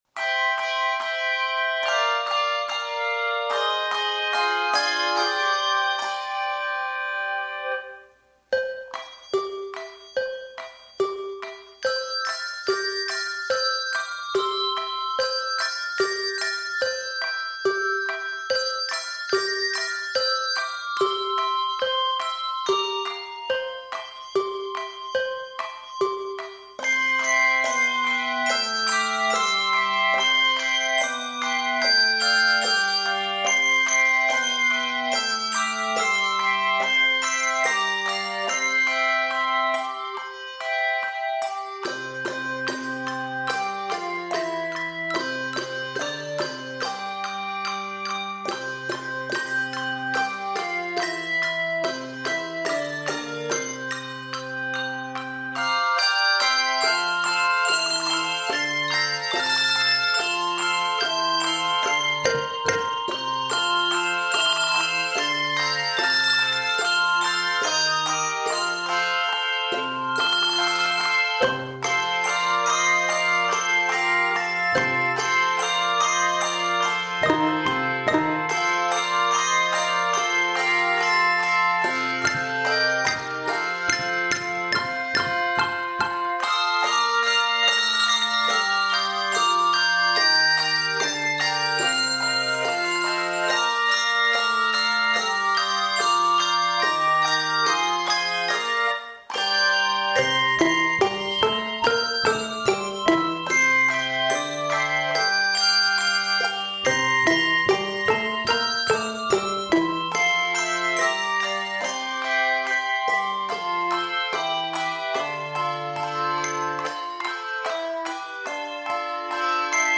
playful and perky arrangement